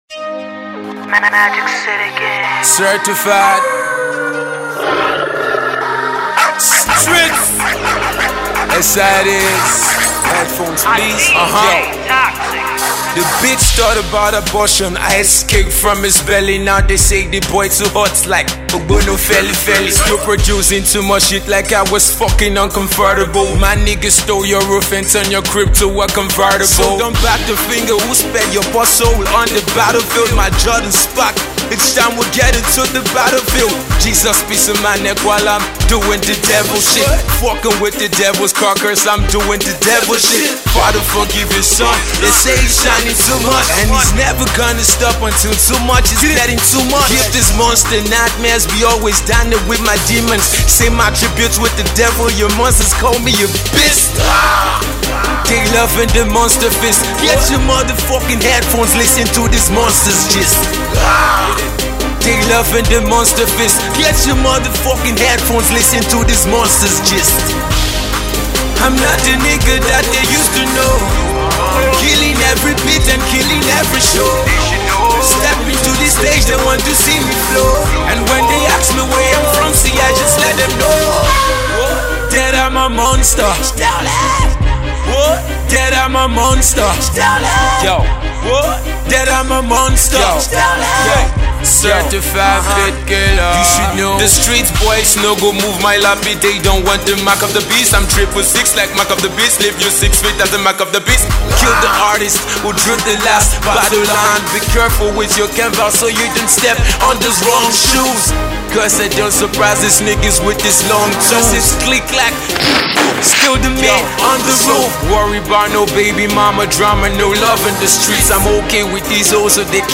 Hip-Hop …
South South Fast Rising Rapper
Stunning HipHop Track